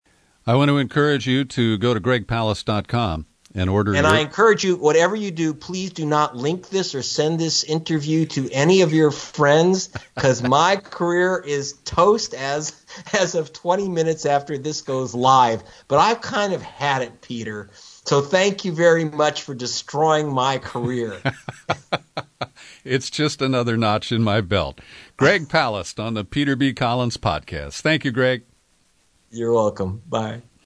Will This Interview Destroy Greg Palast’s Career?
Click to hear what he said in his wrap-up.